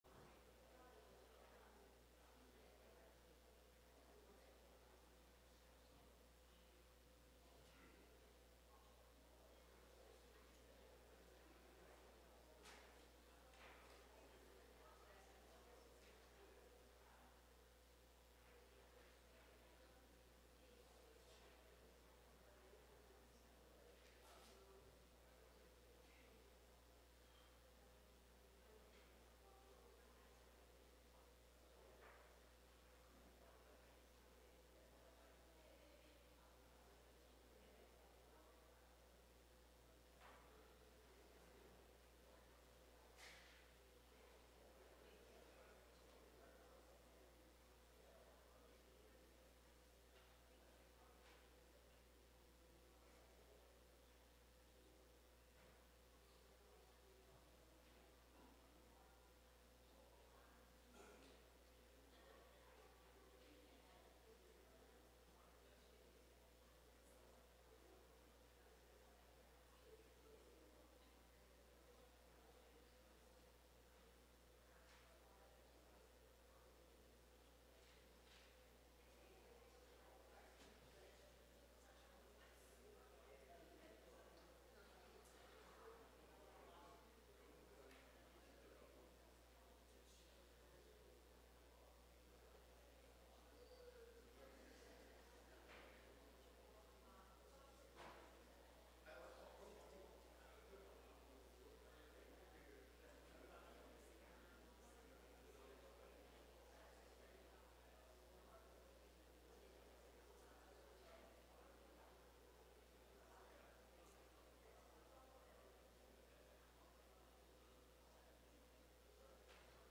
2026年4月12日溫城華人宣道會粵語堂主日崇拜